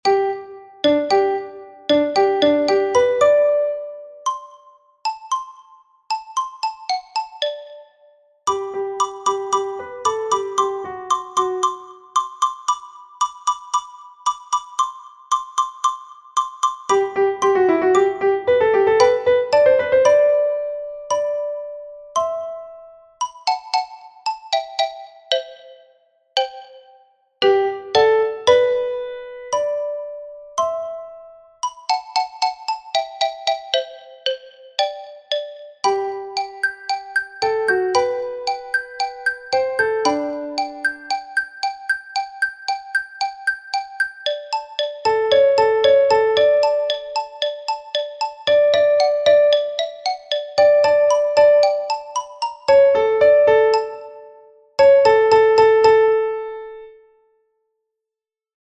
Here you have got the sound file with the silent bars.
Eine_Kleine_Nachtmusik_silencios.mp3